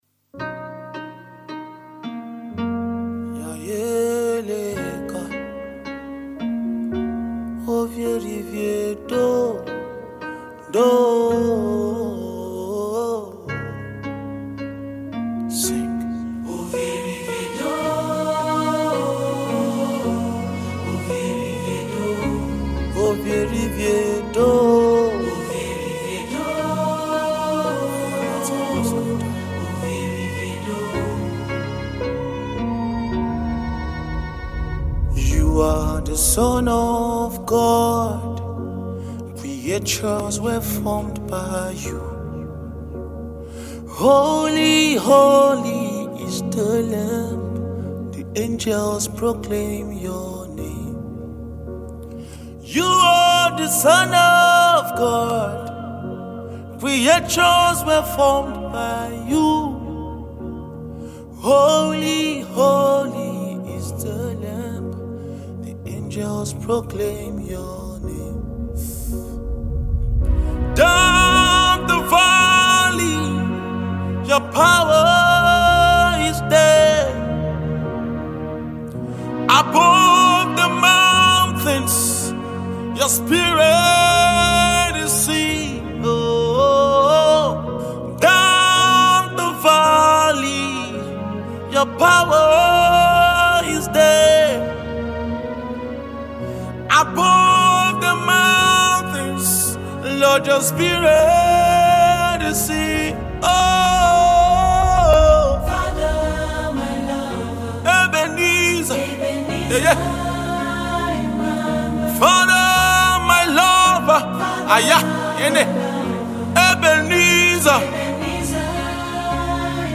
Versatile Nigerian Gospel Music minister